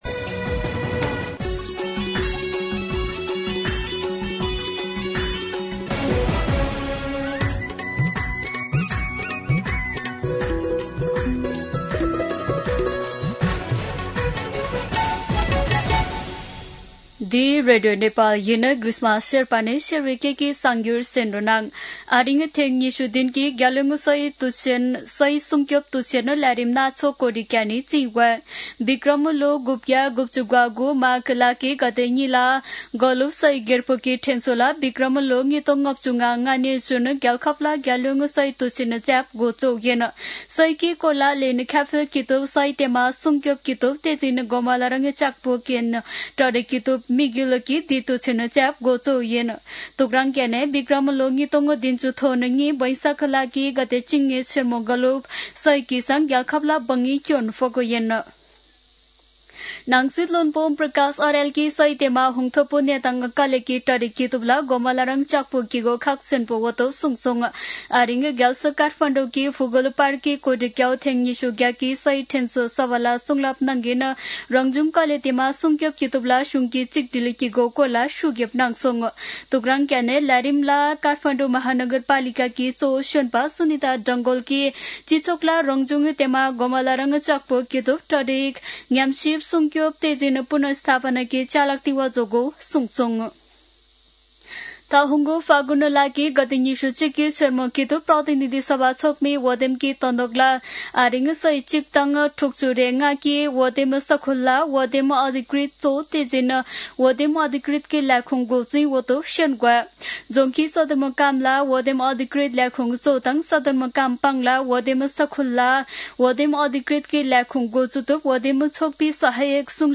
शेर्पा भाषाको समाचार : २ माघ , २०८२
Sherpa-News-10-2-.mp3